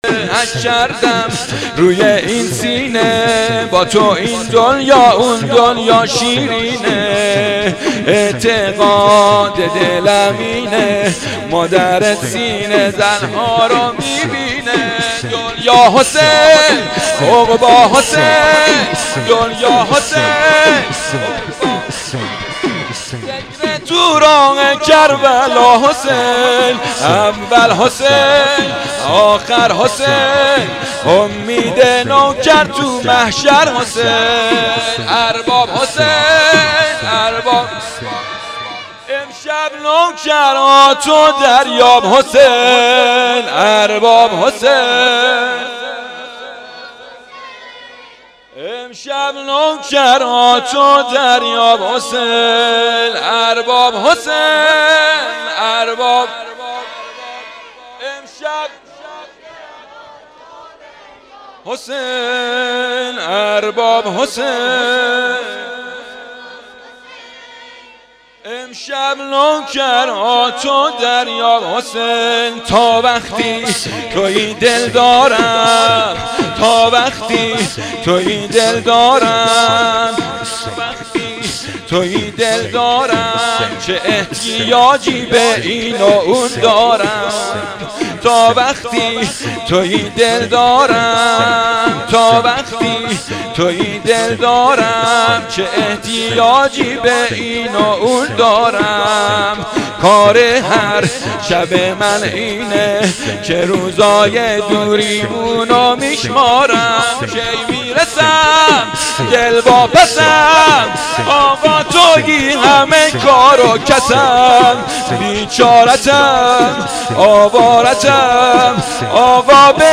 شور| با تو این دنیا اون دنیا شیرینه
شهادت امام باقر